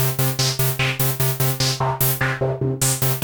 SI2 NOIZGATE.wav